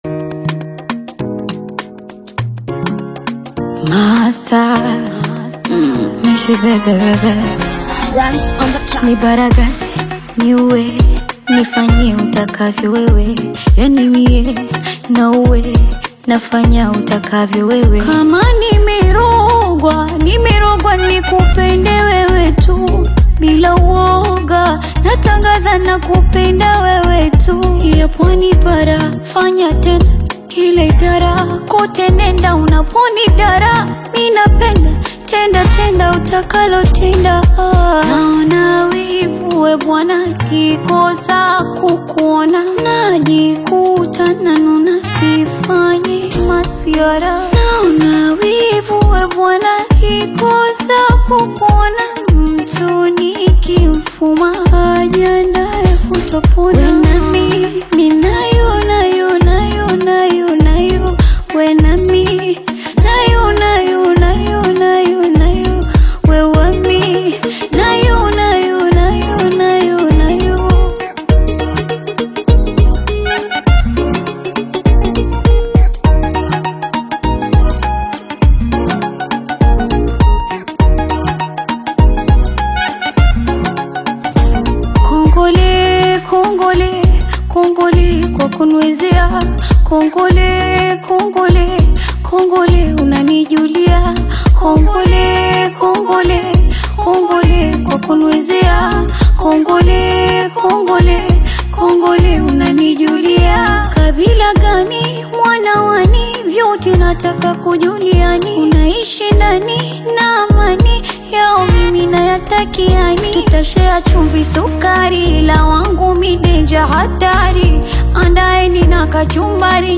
Afro-Pop/Bongo-inspired single